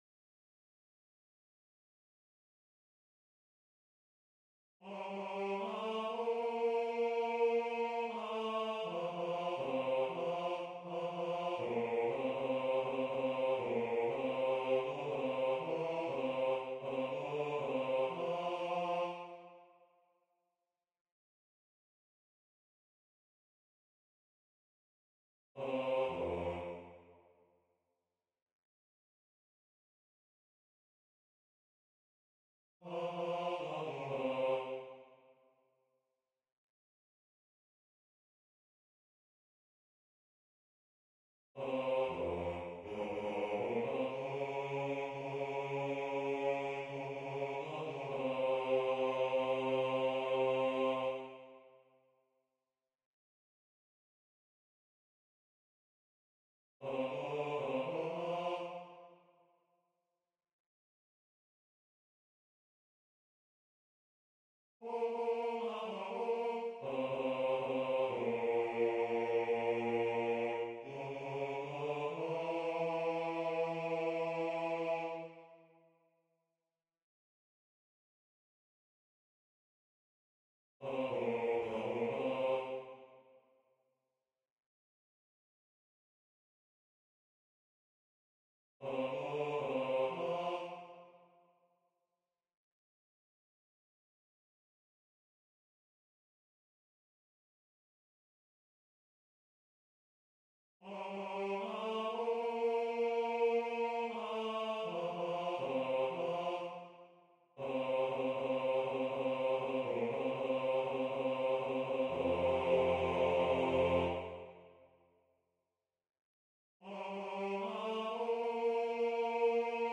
Bajo
MAITENA-Bajo.mp3